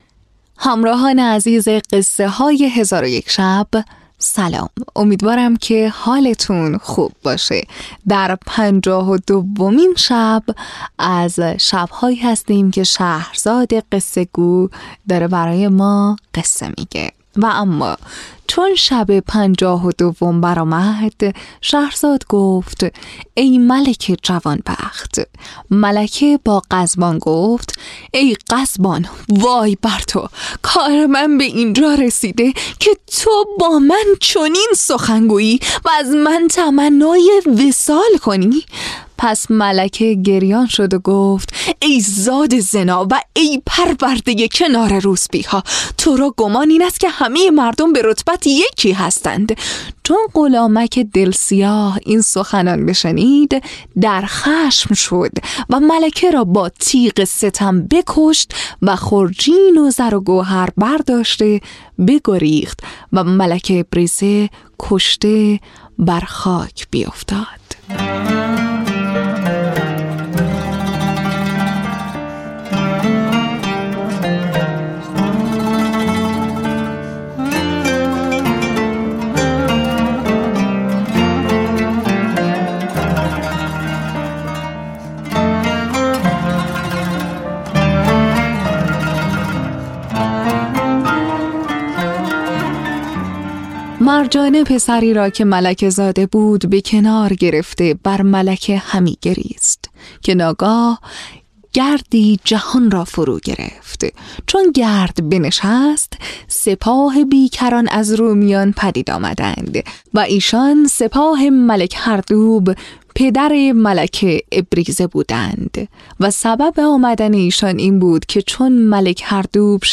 تهیه شده در استودیو نت به نت